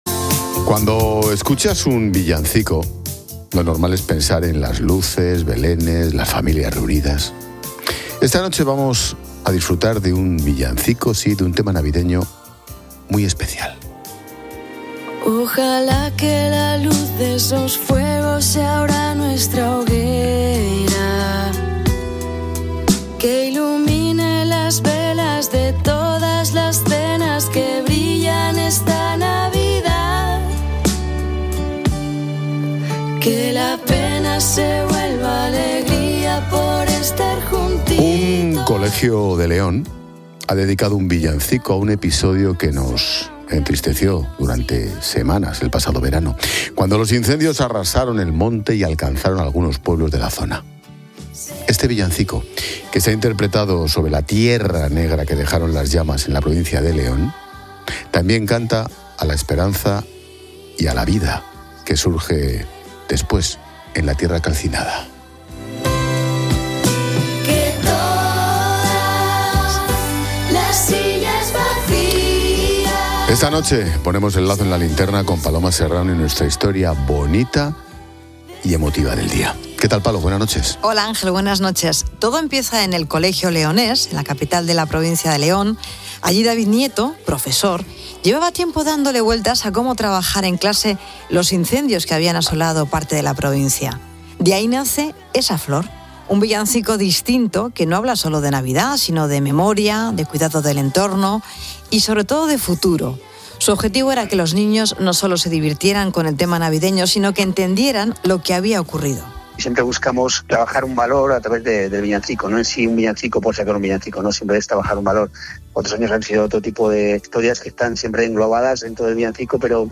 Profesor del colegio